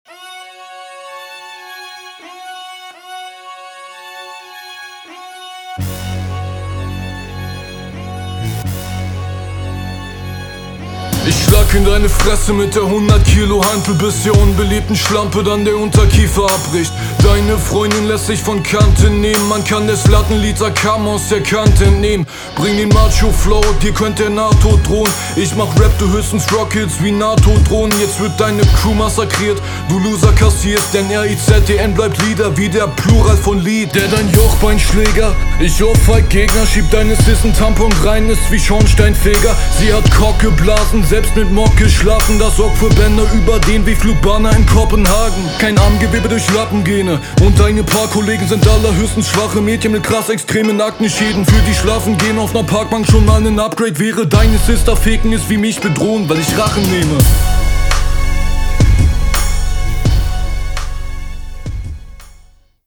Schnelles Battle Format